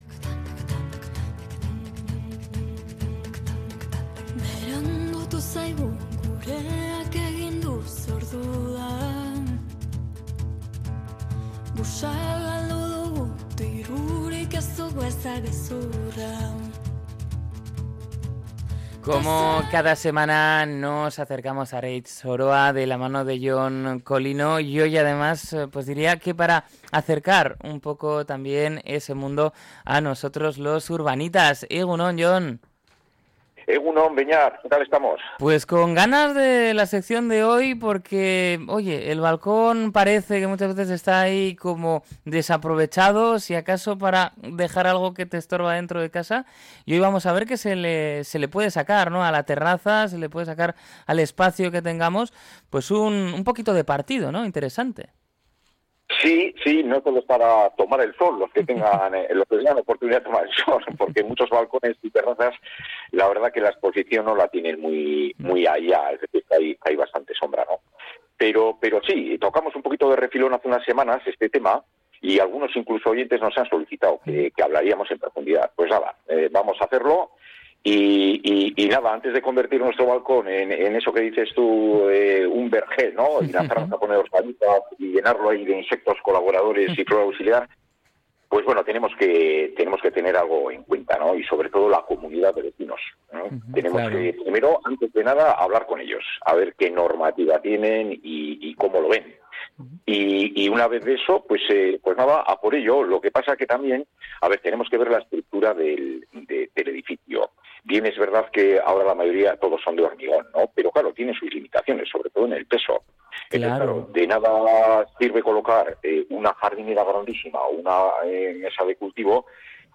Durante la conversación ha explicado que estos espacios, a menudo infrautilizados, pueden convertirse en zonas productivas si se planifican correctamente.